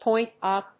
アクセントpóint úp